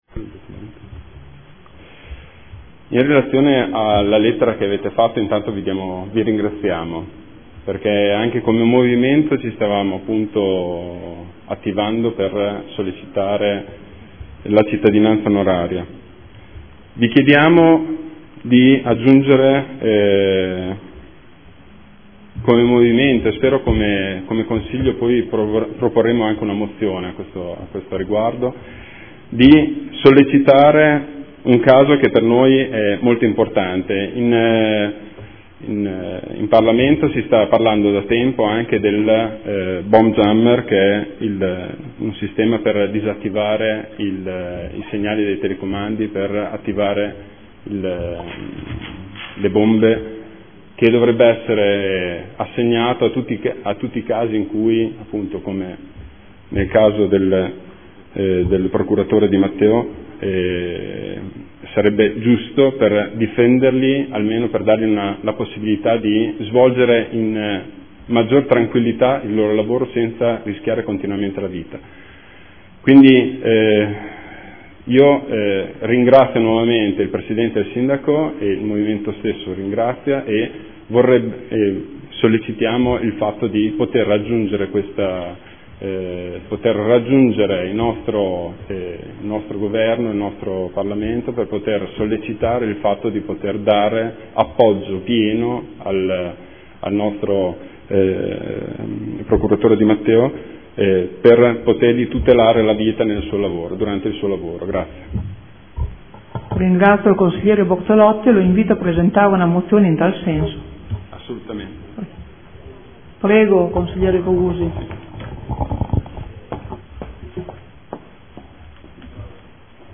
Seduta del 27/11/2014 interviene su Cittadinanza Onoraria al Dr. Di Matteo.